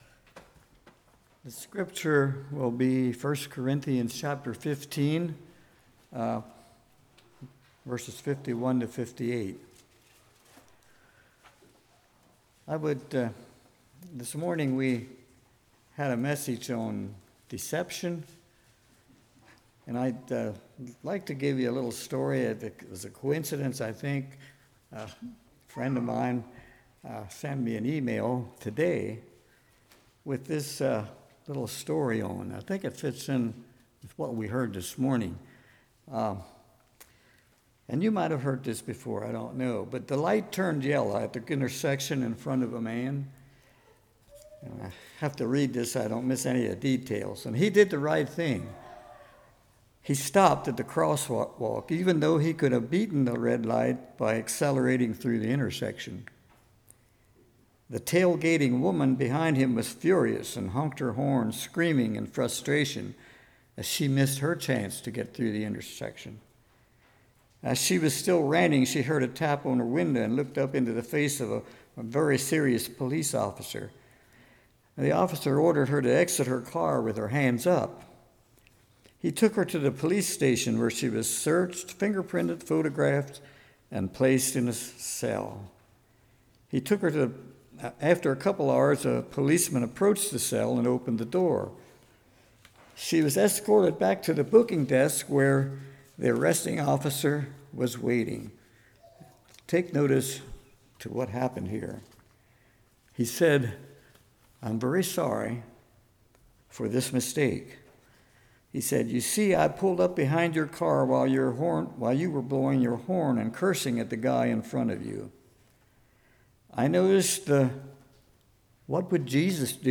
1 Corinthians 15:51-58 Service Type: Evening Death is sure Attitude towards death Are you ready to die?